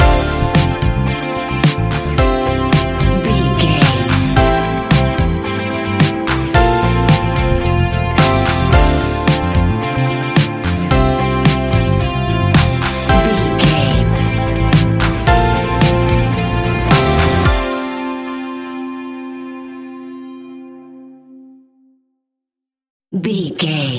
Ionian/Major
ambient
electronic
new age
chill out
downtempo
pads